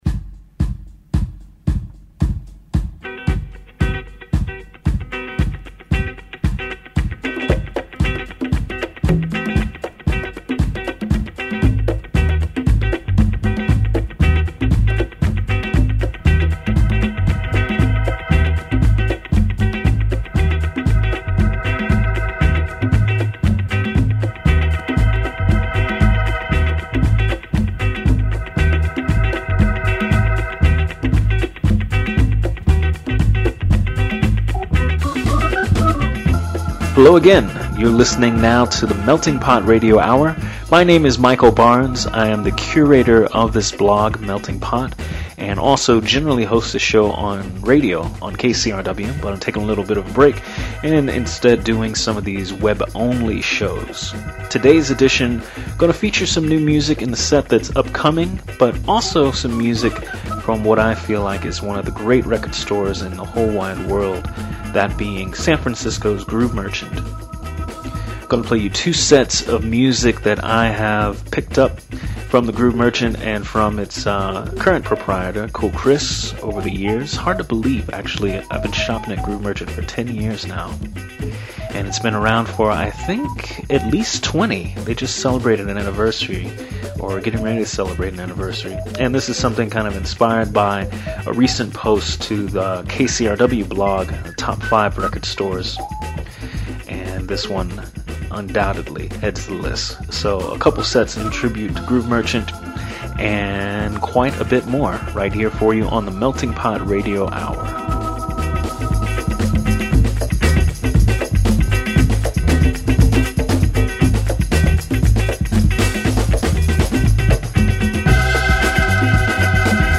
pretty mellow one at that